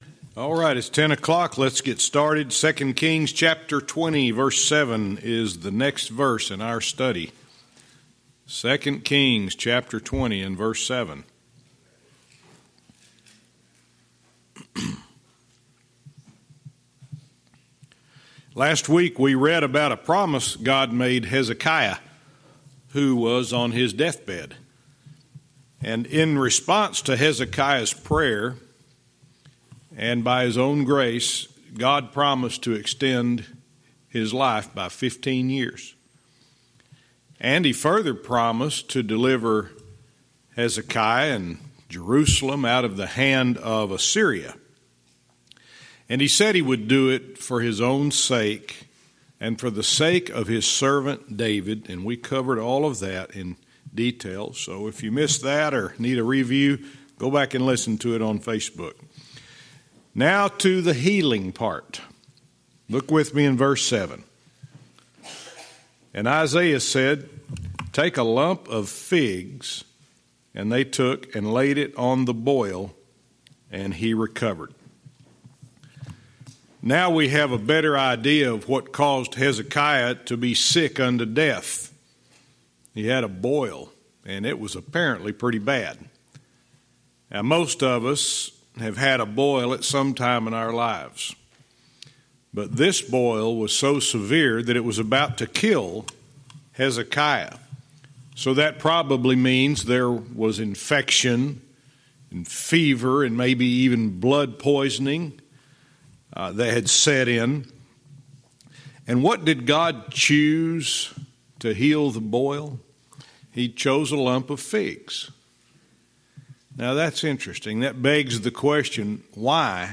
Verse by verse teaching - 2 Kings 20:7-10